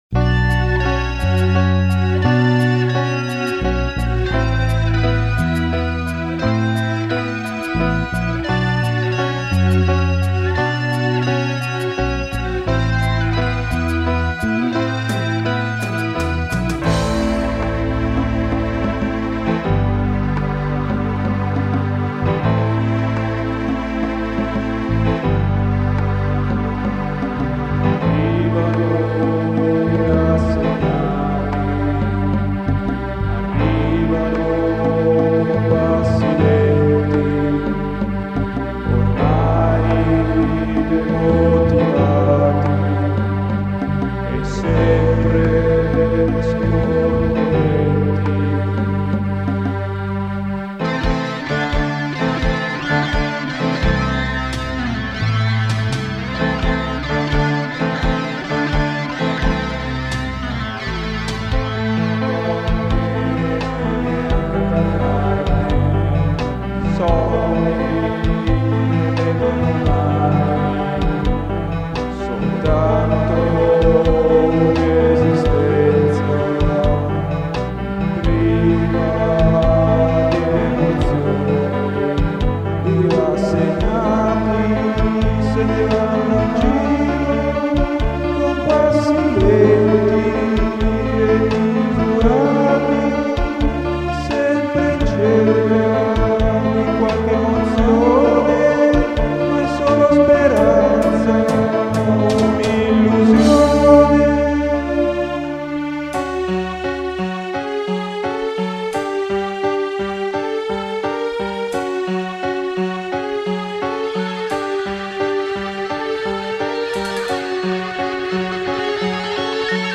happy vocal
far backing vocals
bass
bell & guitars